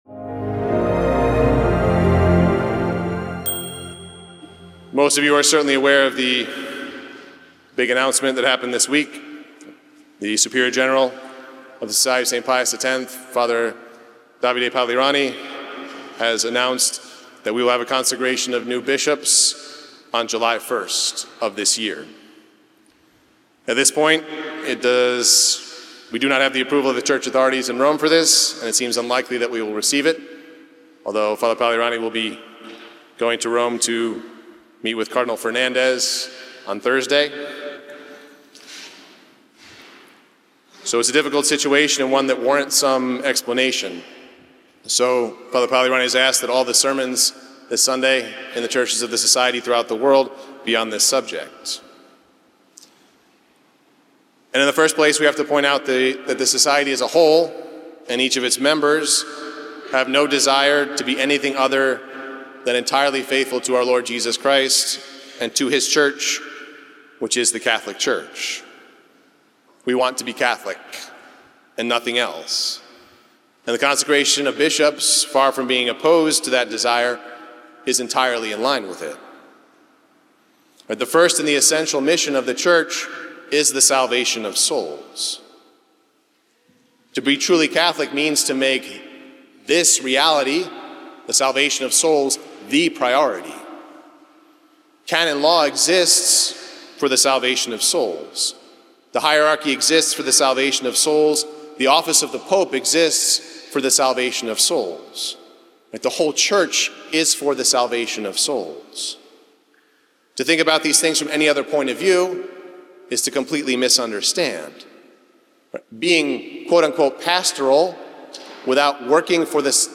Podcast Sermon: Why Consecrations are Necessary